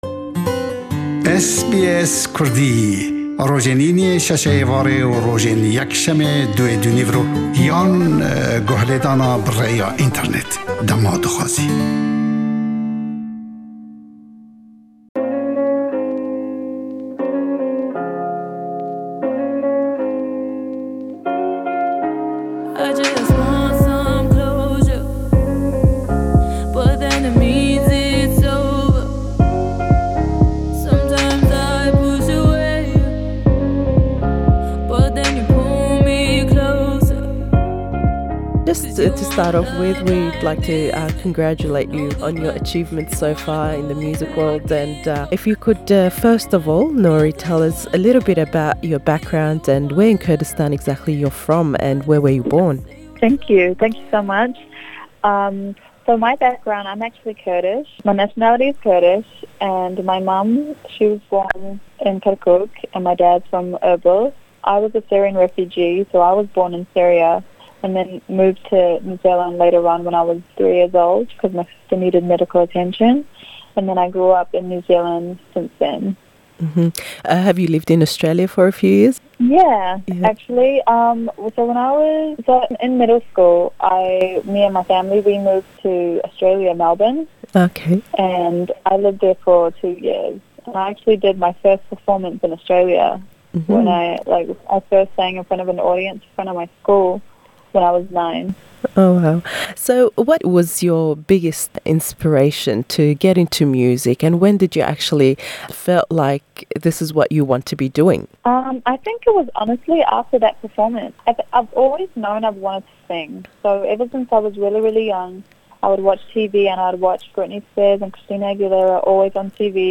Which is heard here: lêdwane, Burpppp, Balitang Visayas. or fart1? lêdwane